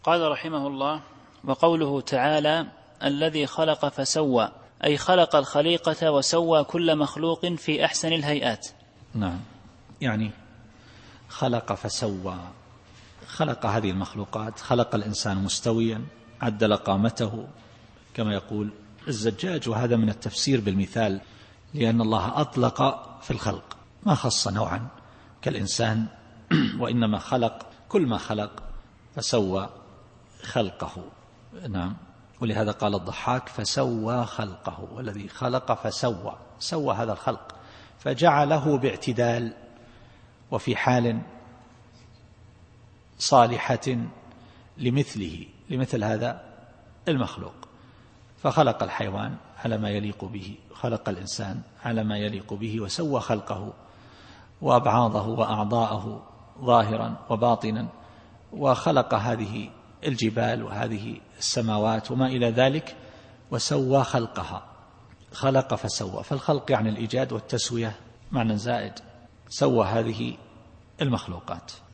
التفسير الصوتي [الأعلى / 2]